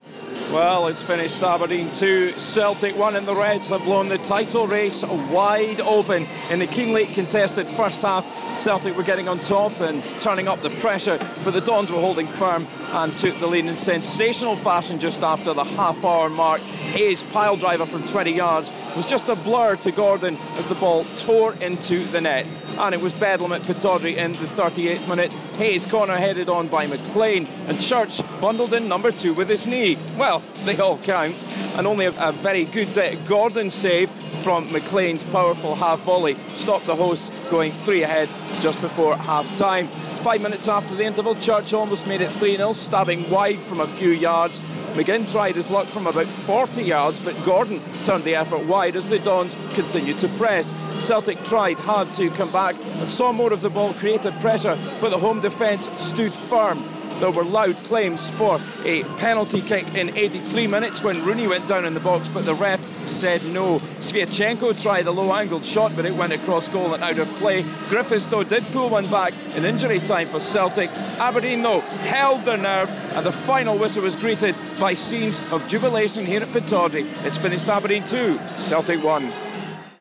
Listen : Aberdeen 2-1 Celtic - match report